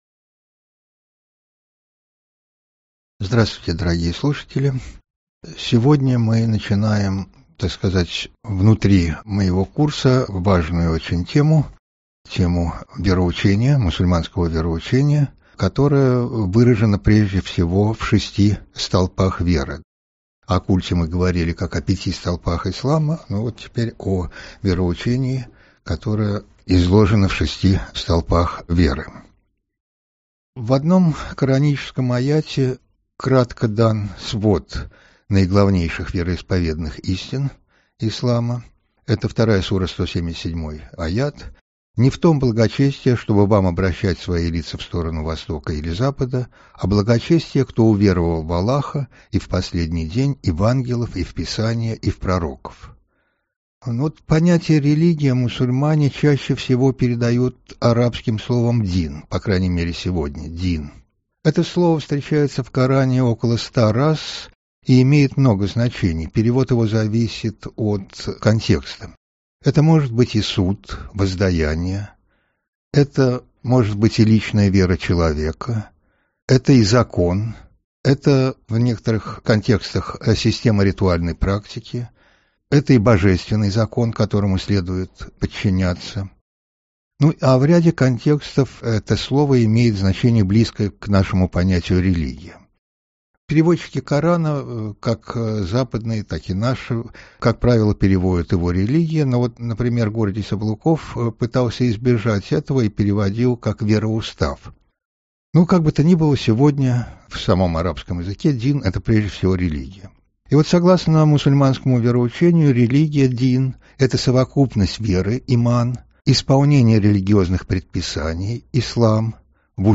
Аудиокнига Единобожие. Бог и человек | Библиотека аудиокниг